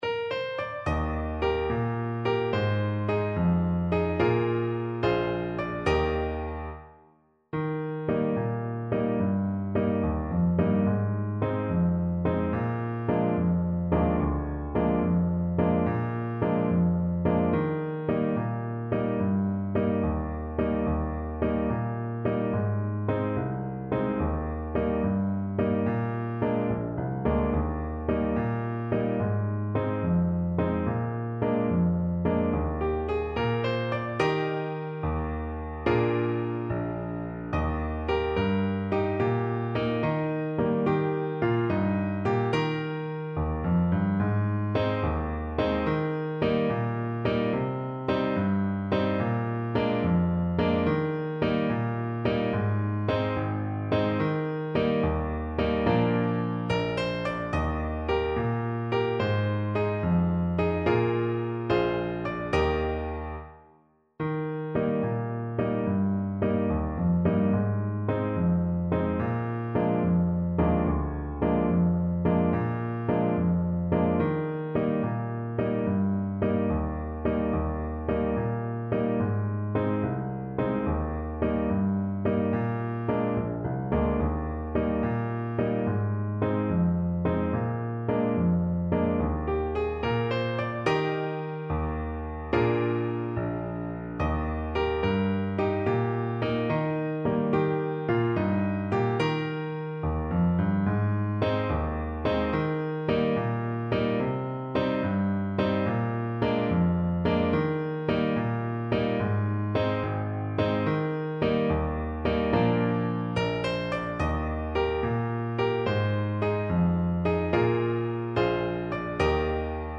Play (or use space bar on your keyboard) Pause Music Playalong - Piano Accompaniment Playalong Band Accompaniment not yet available transpose reset tempo print settings full screen
Trombone
6/8 (View more 6/8 Music)
Eb major (Sounding Pitch) (View more Eb major Music for Trombone )
Allegro .=c.100 (View more music marked Allegro)
Traditional (View more Traditional Trombone Music)